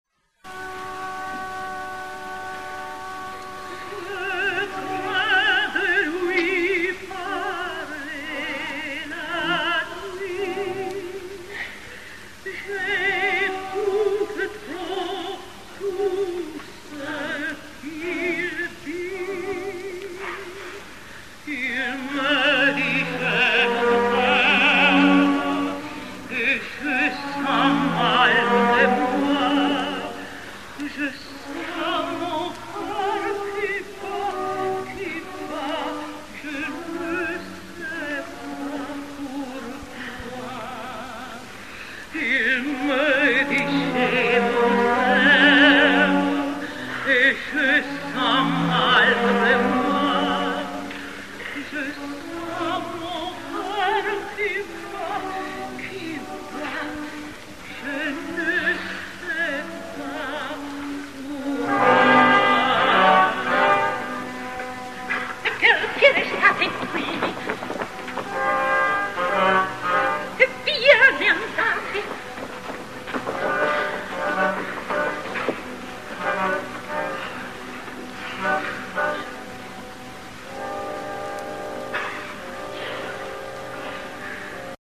Джинна Педерцини (меццо-сопрано)